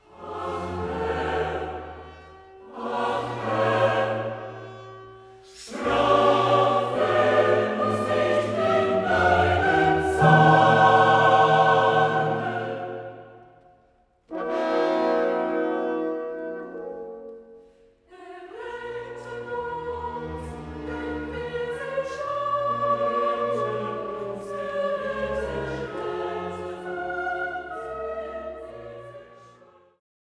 Chor der Israeliten